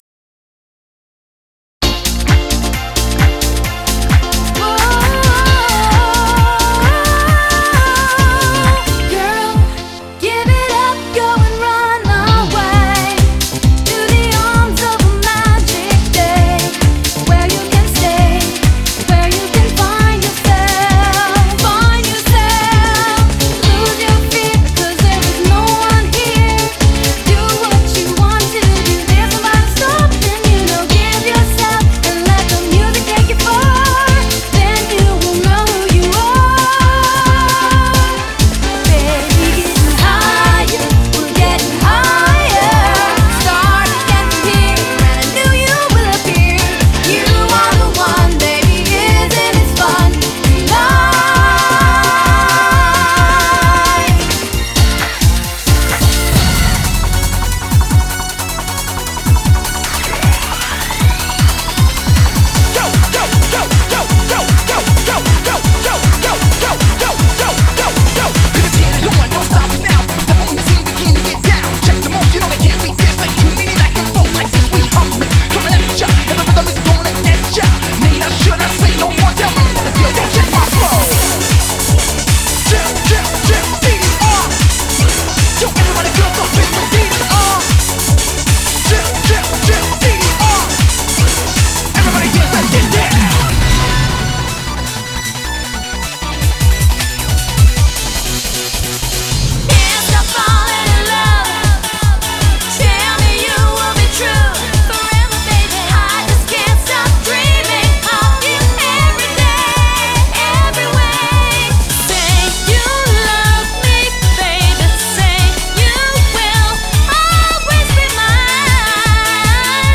BPM128-260
Audio QualityPerfect (High Quality)
Megamix done by Me